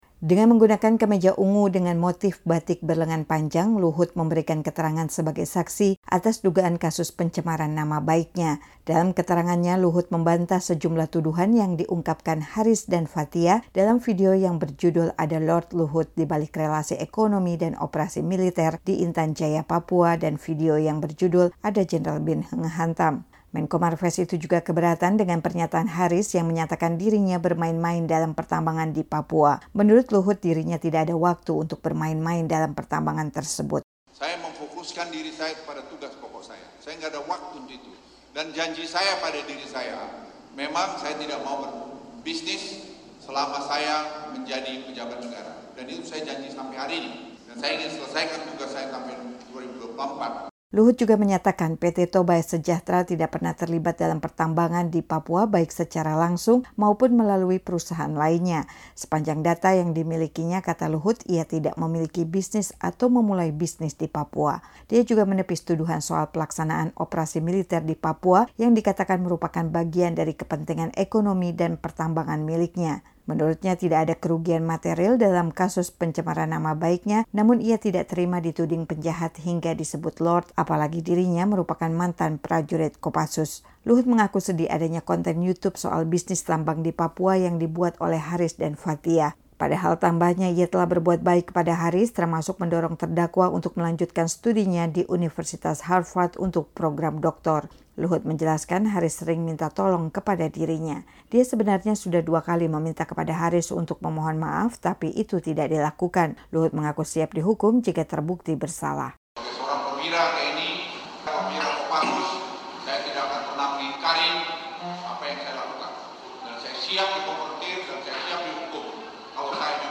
Agenda sidang kali ini mendengarkan keterangan saksi pelapor, yakni Menko Marves RI Luhut Binsar Pandjaitan.